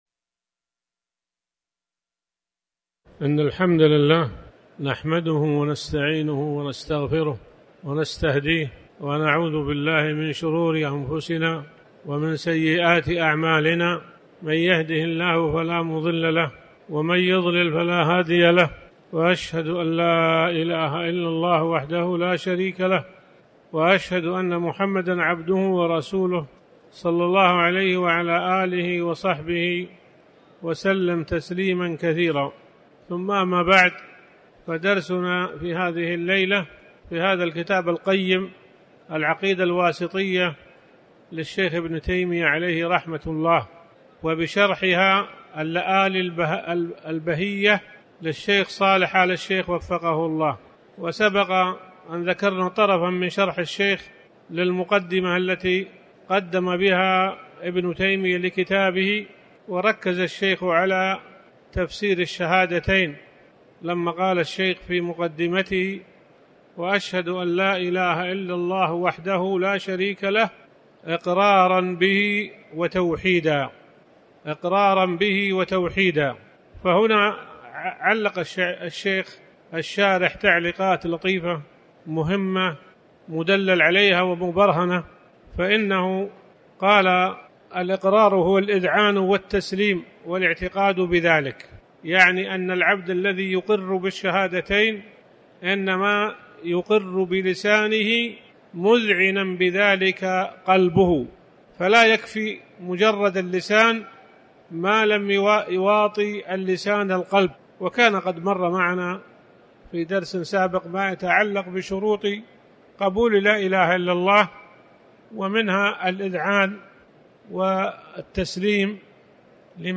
تاريخ النشر ٤ شعبان ١٤٤٠ هـ المكان: المسجد الحرام الشيخ